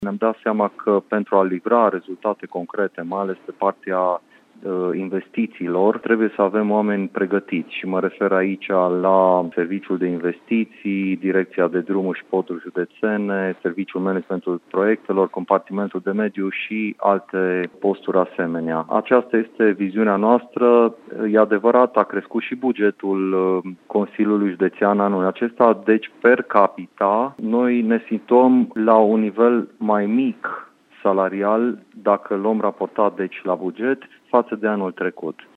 Cele mai multe dintre aceste posturi sunt tehnice și vor fi ocupate fie prin transferuri, fie prin concurs, explică administratorul public al județului, Marian Vasile.